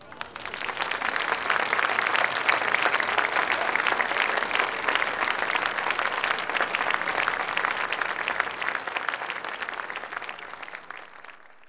clap_026.wav